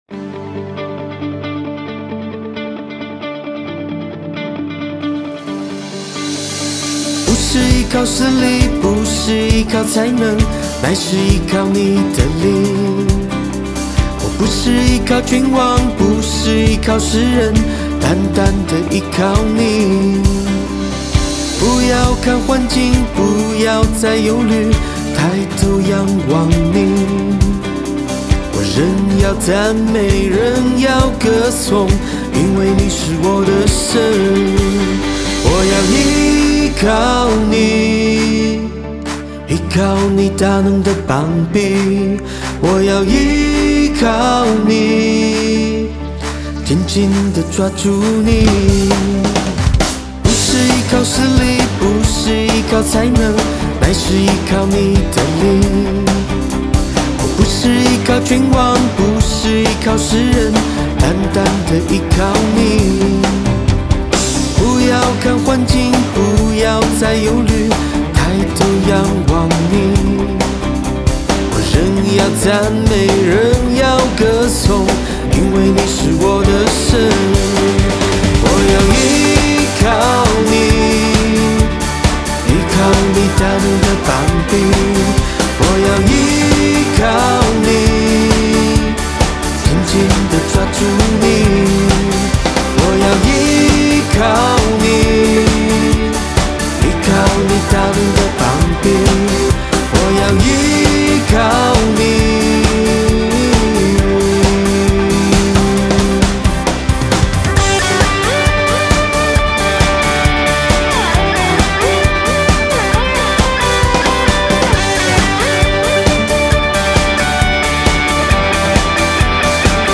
電吉他
爵士鼓
前奏 → 主歌 → 副歌 → 主歌 → 副歌 → 間奏 → 橋段(四遍) → 副歌 → 副歌上半(兩遍) → 尾句